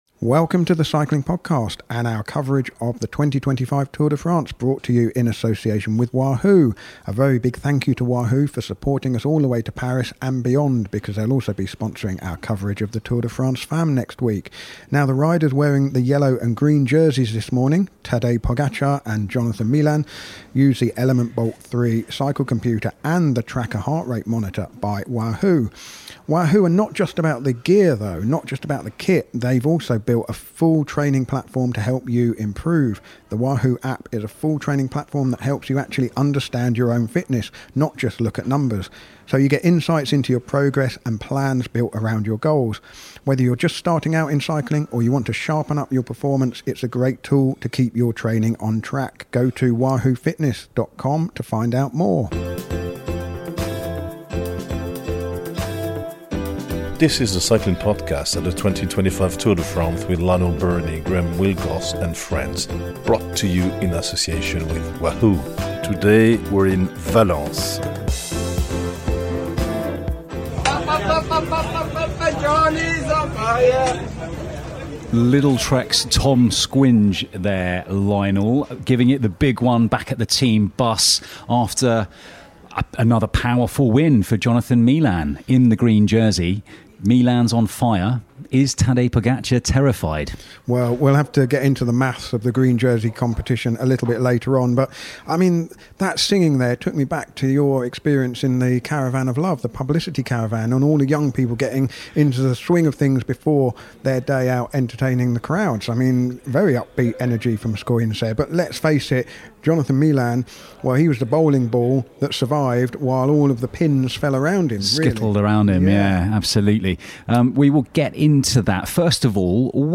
We’re back on the road for the 13th time with daily episodes recorded at the heart of the world’s biggest race. Our nightly episodes feature race analysis, interviews and plenty of French flavour.